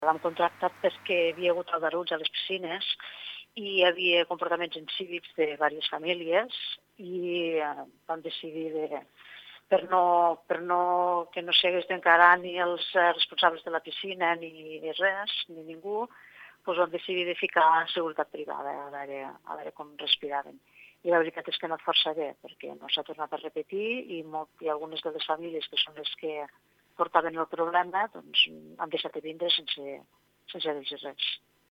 La regidora Núria Palau explica per què han contractat seguretat privada | Descarrega'l com a: | MP3